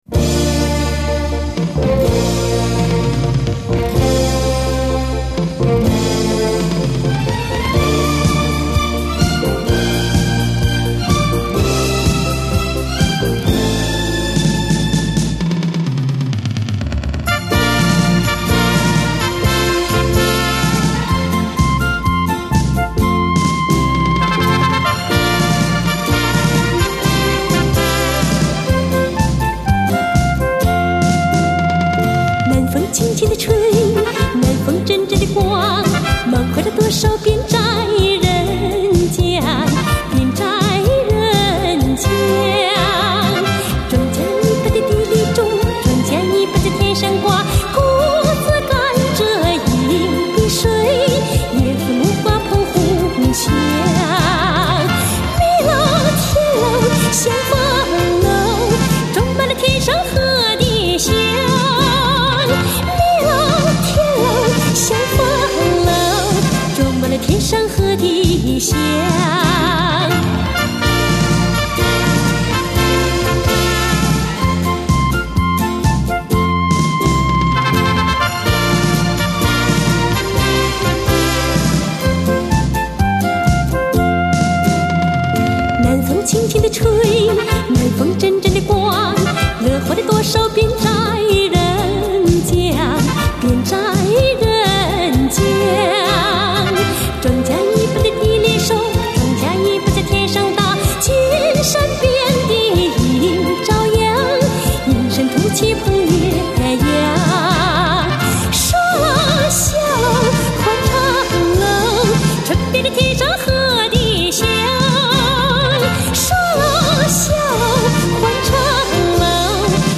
音乐风格: 流行
音色甜美，融通俗、民族和美声于一体。